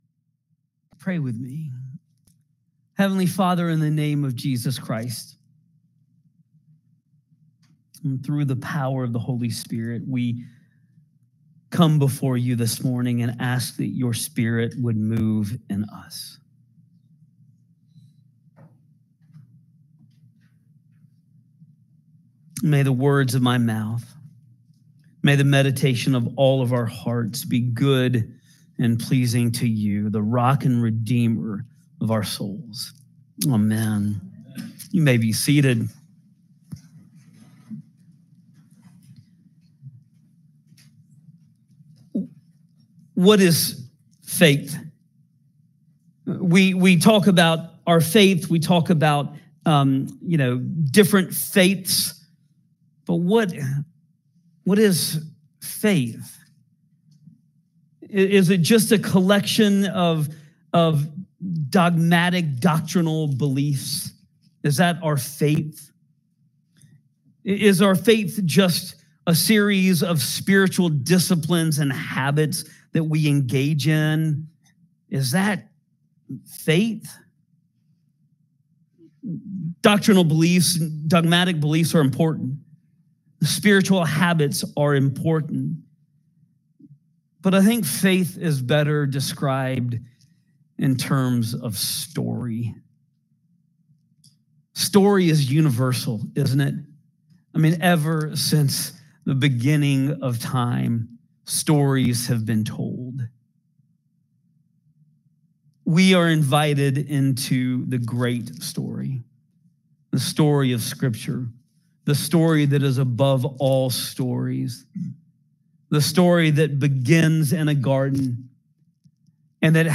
St Barnabas Sermons: